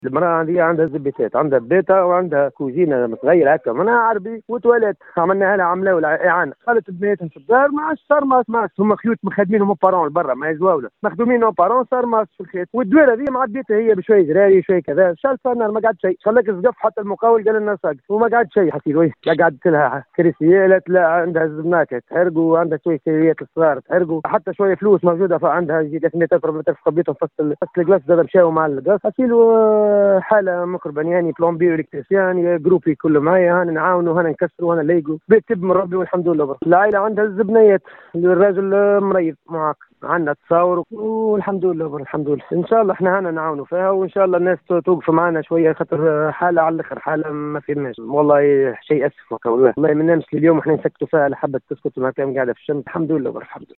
واشار المتحدث في تصريح ل ام اف ام، إلى أن المنزل في حاجة الى إعادة الترميم إلا ان الوضعية الاجتماعية للعائلة لا تسمح بذلك حتى إن الزوجة كان تملك حوالي 300 دينار مخبأة في الخزانة إلا انها احترقت مع باقي التجهيزات، وفق قوله.